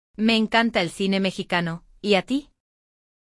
Nosso diálogo desta edição traz uma conversa entre duas amigas que compartilham seus gostos por filmes.